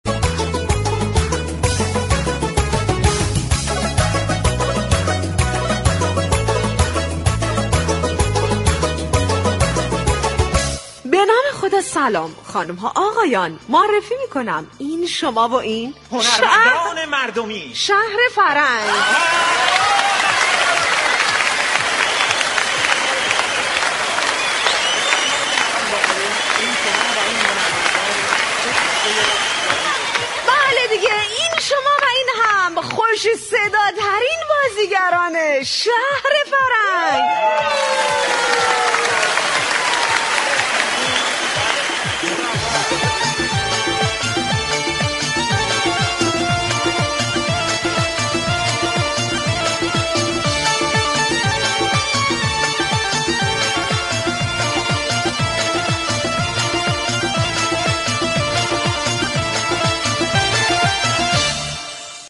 اجرای نمایش طنز با موضوع تفاوت نسل ها در جنگ شاد "شهر فرنگ" روز چهارشنبه پنج آبان
به گزارش روابط عمومی رادیو صبا ، " شهر فرنگ " در قالب جنگ مفرح با نمایش های طنز و شاد هر روز از رادیو صبا پخش می شود .
این برنامه با پخش موسیقی و ترانه های نشاط آفرین به همراه آیتمهای نمایشی كوتاه طنز و لطیفه های شیرین با بیان طنز به نقد مسایل سیاسی ، اقتصادی، ورزشی ، اجتماعی در قالب نمایش های طنز و لطیفه می پردازد.